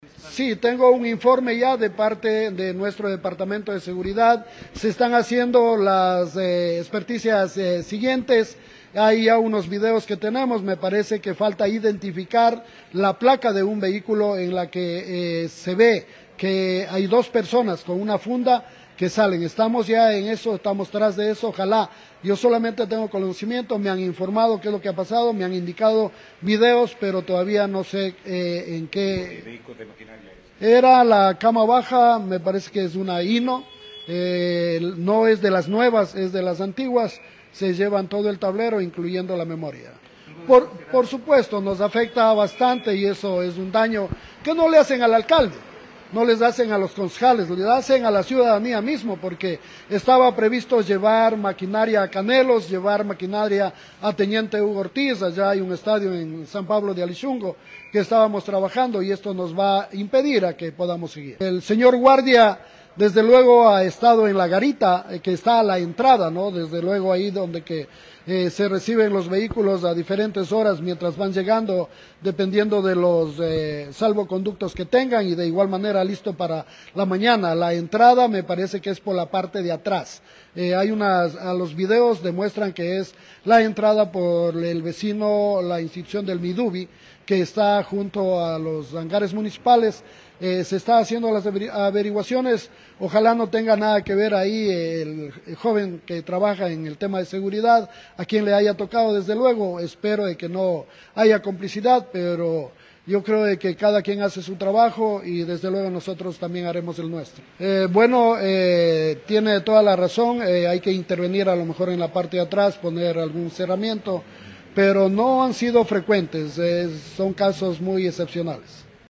Germán Flores, alcalde de Pastaza.